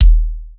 808-Kicks09.wav